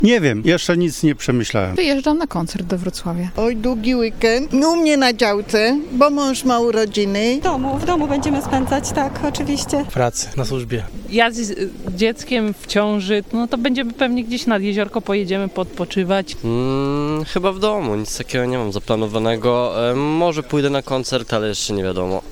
Mieszkańcy Stargardu podzielili się z nami swoimi planami na spędzenie wolnego czasu.